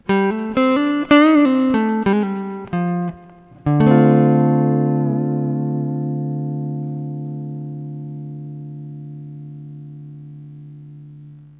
Записано все на советский конденсаторный микрофон (или в линию).
чистый звук через преамп Уже лучше :)